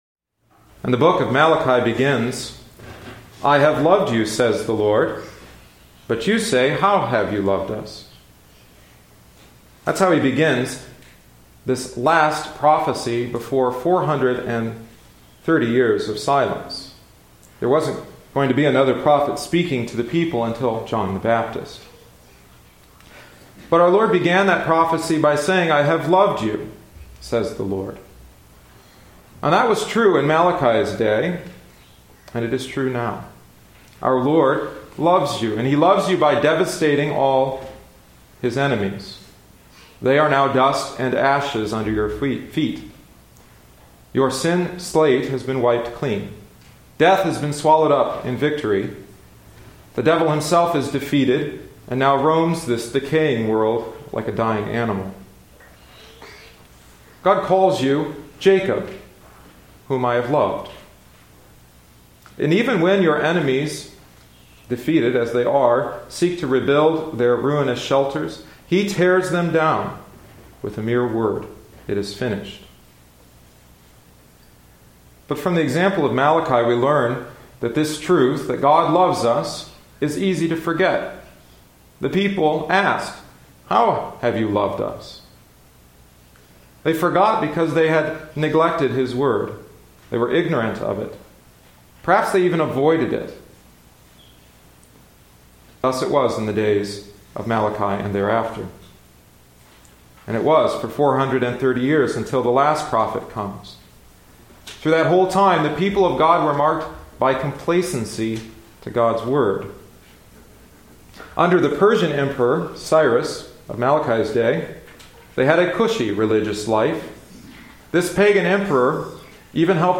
Sermons – Page 16 – Outer Rim Territories